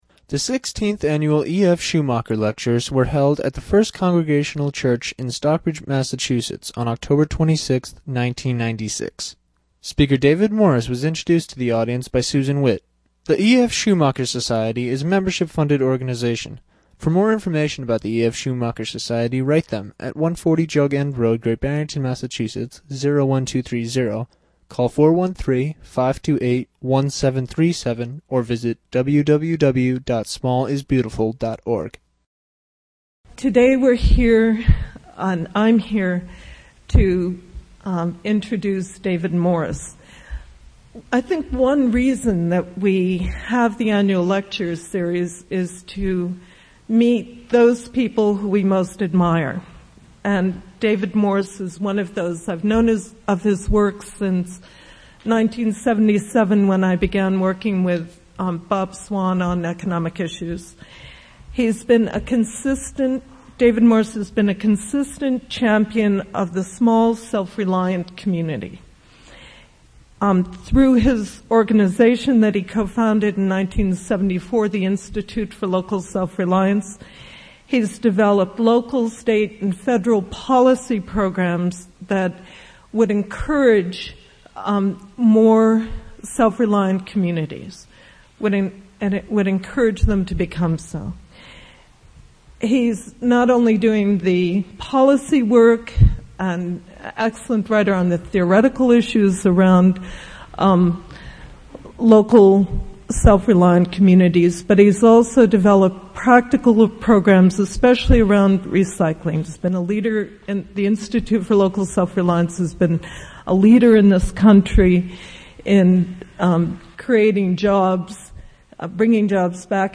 ‘Reclaiming Community’ Talk from Schumacher Lectures.